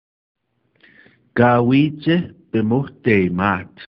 kā-wīci-pimohtēmāt (pronounced: